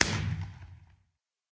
blast_far1.ogg